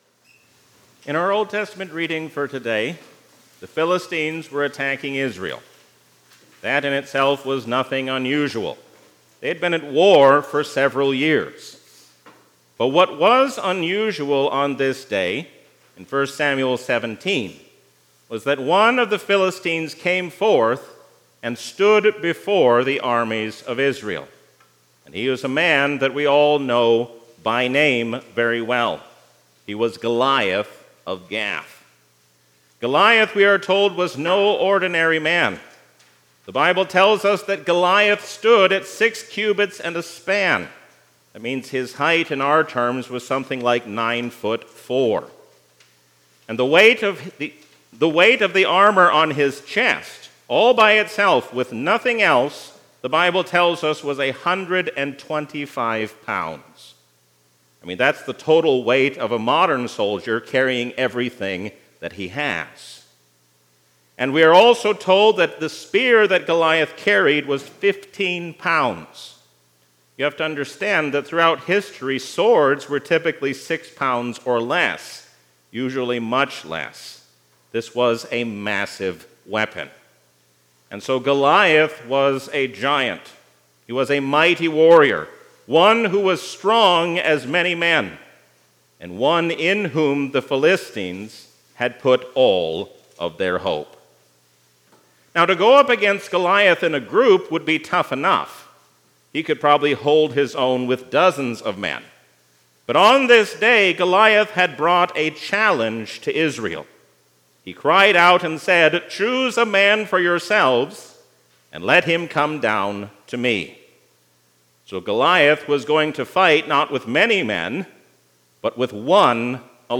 A sermon from the season "Trinity 2021."